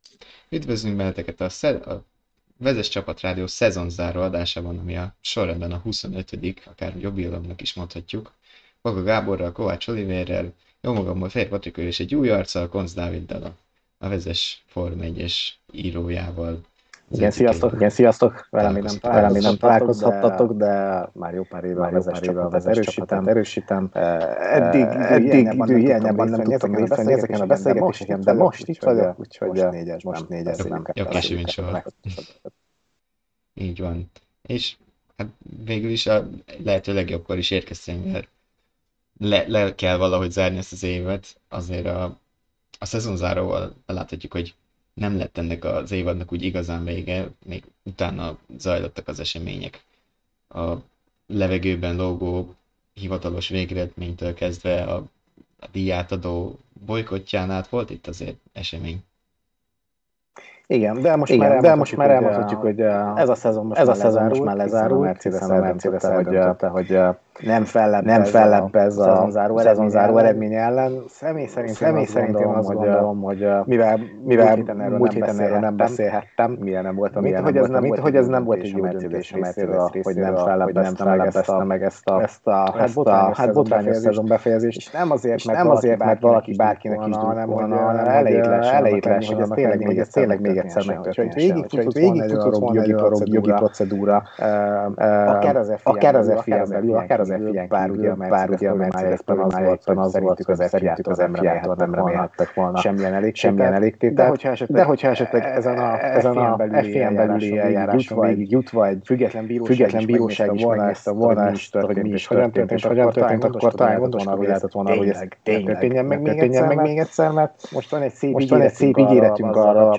Minden adás élő beszélgetéssel születik, amit nézhettek a Vezess YouTube-csatornáján, ahol kérdezhettek is.